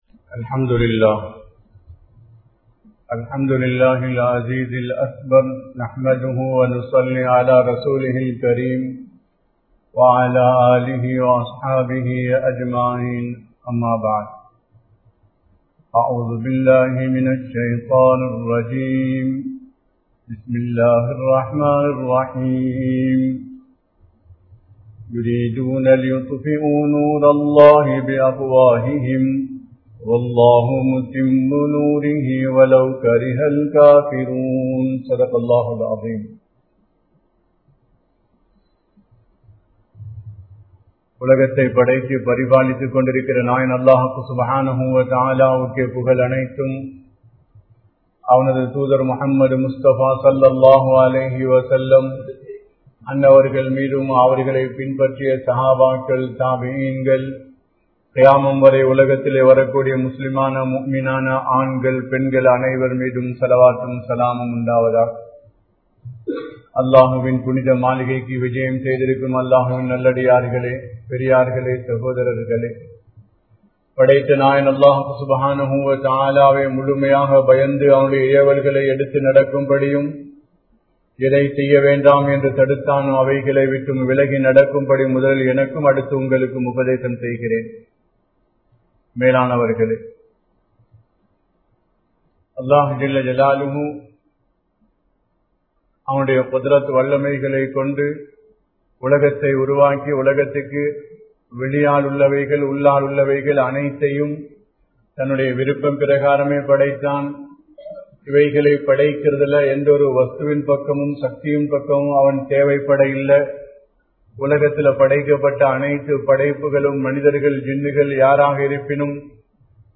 Makkalai Nervaliyin Pakkam Alaippathu Musleemkalin Poruppu(மக்களை நேர்வழியின் பக்கம் அழைப்பது முஸ்லீம்களின் பொறுப்பு) | Audio Bayans | All Ceylon Muslim Youth Community | Addalaichenai